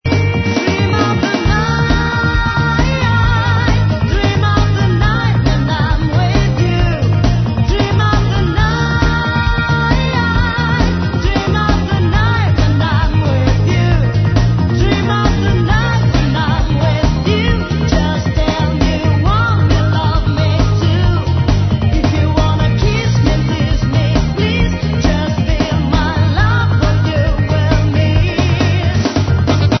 Pop radio